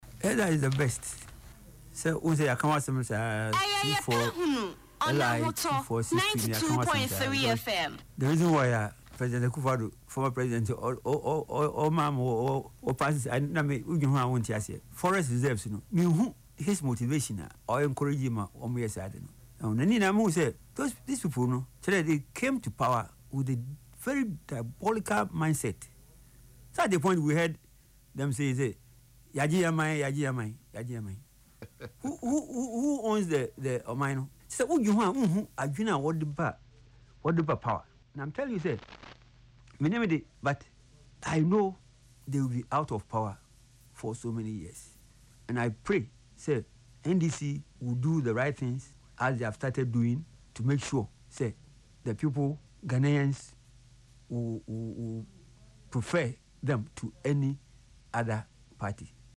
Reacting to the development on Ahotor FM’s “Yepe Ahunu” show on Saturday, November 1,